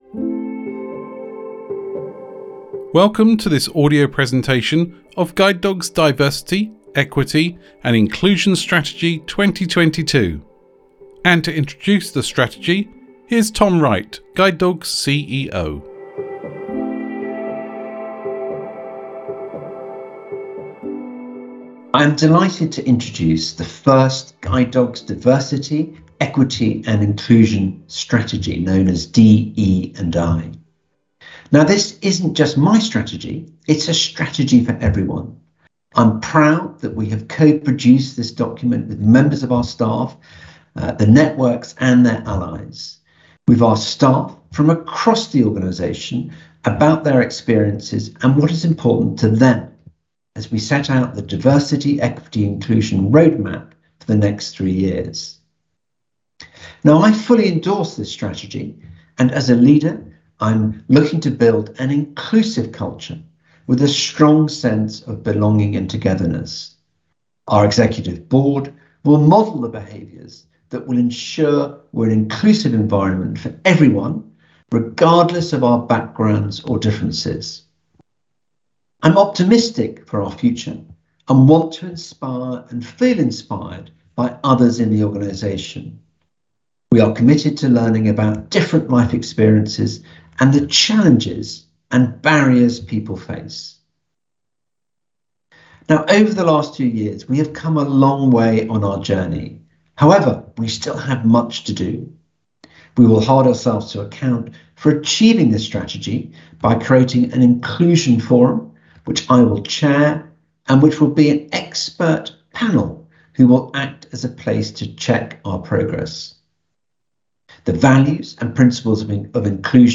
Diversity, Equity and Inclusion Strategy audio book